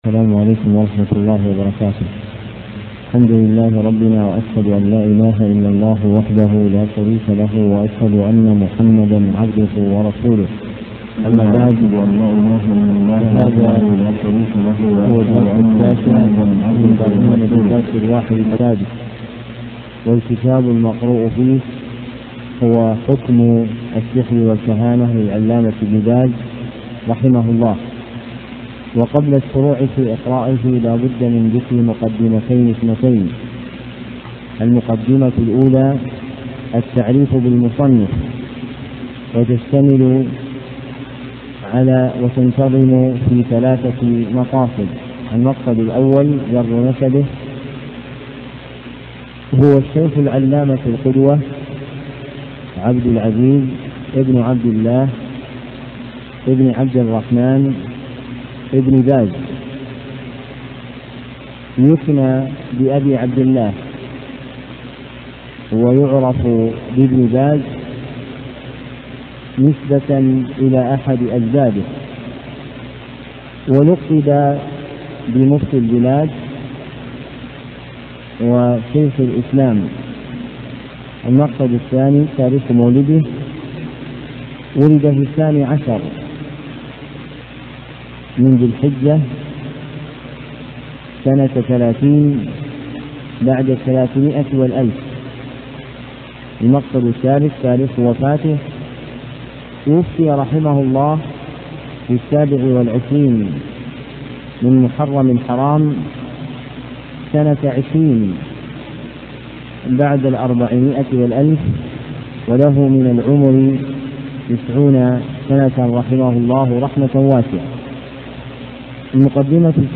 محاضرة صوتية نافعة،